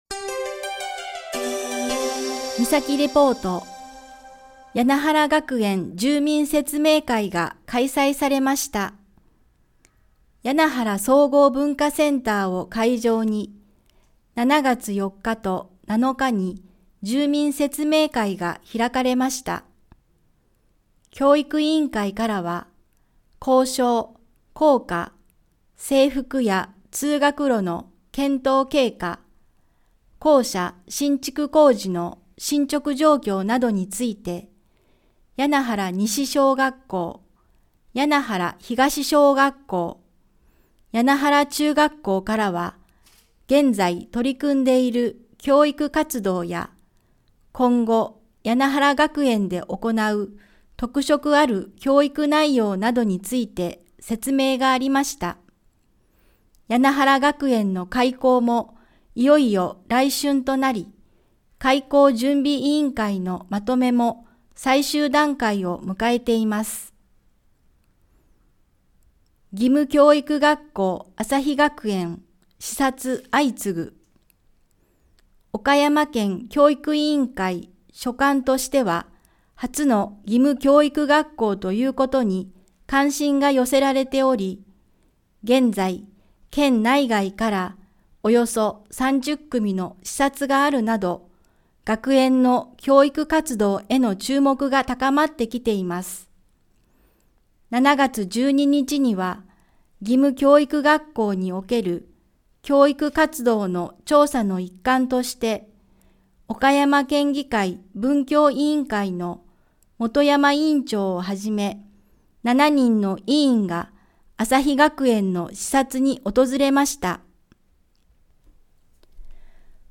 声の広報
広報誌の一部を読み上げています。